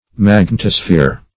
magnetosphere \mag*net"o*sphere\, n.